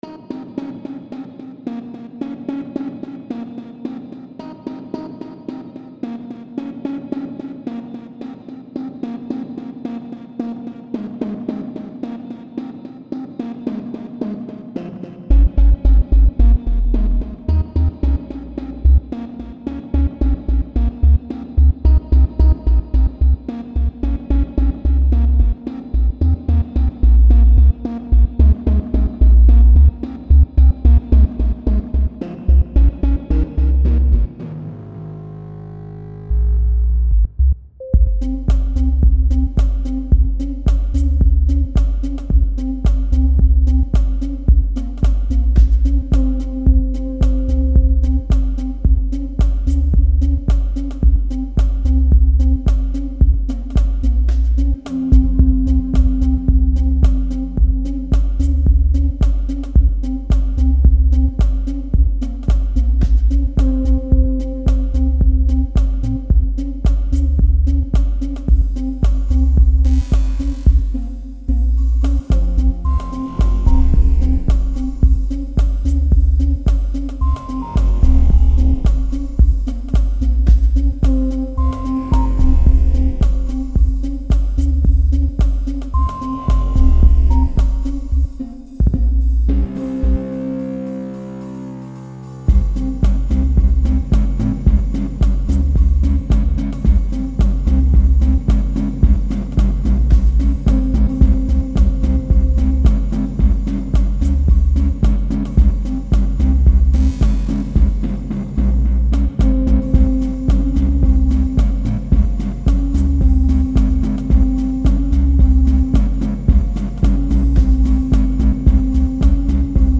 Frischer Sound aus den Archiven